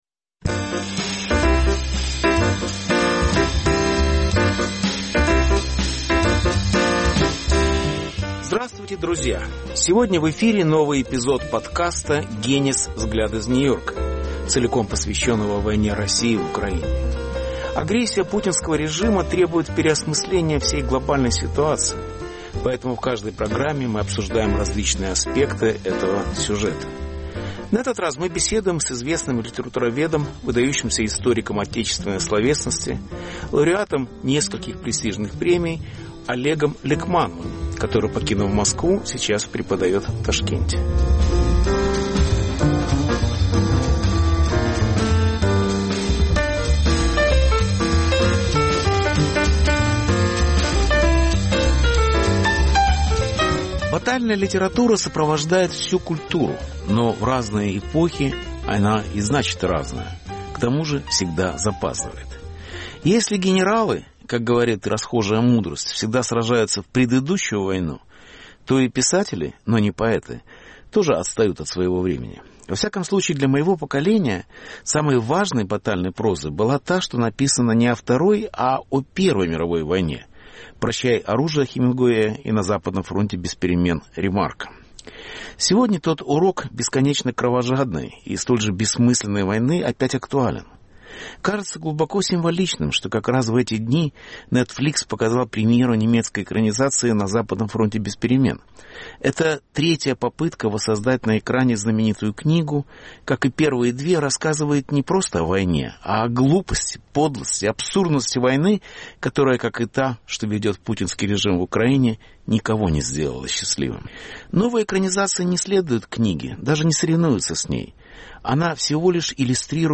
Повтор эфира от 06 ноября 2022 года.